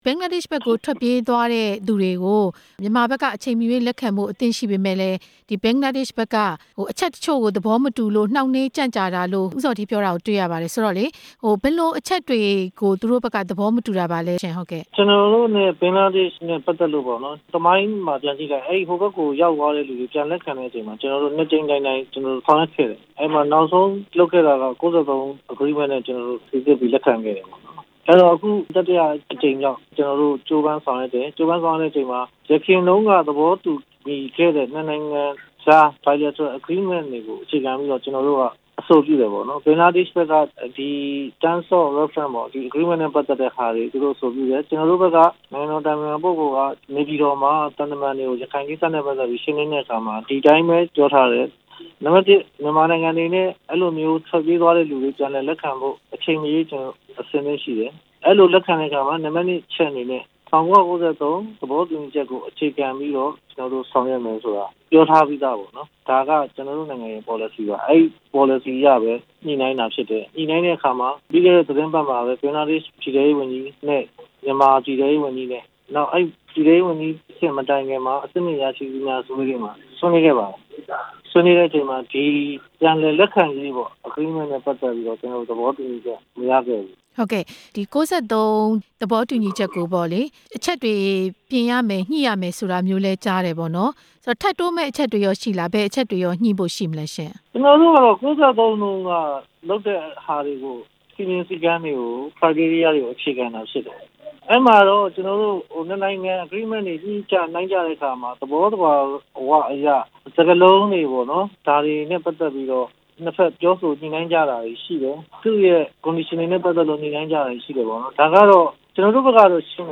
ထွက်ပြေးသွားသူတွေ ပြန်ခေါ်ရေး ဦးဇော်ဌေးနဲ့ မေးမြန်းချက်